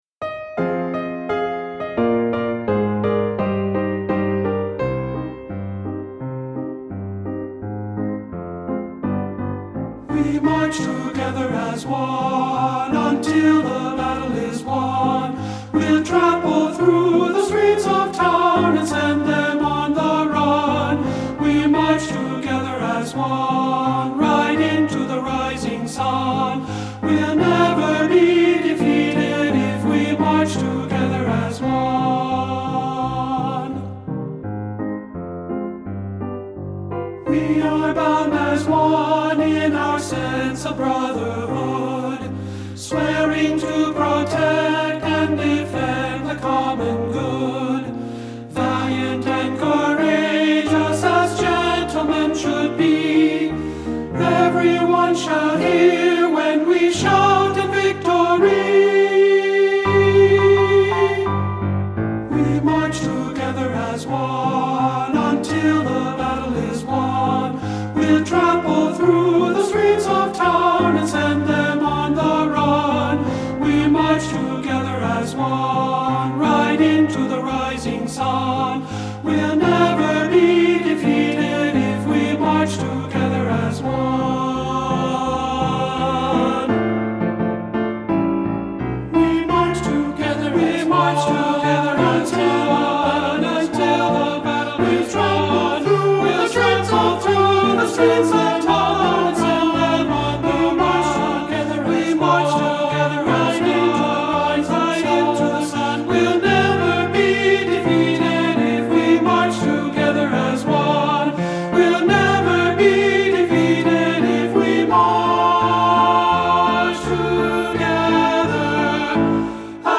Two-Part Unchanged Tenor Voices with Piano
• Piano
Ensemble: Tenor-Bass Chorus
Accompanied: Accompanied Chorus